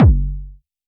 RDM_Copicat_SY1-Kick03.wav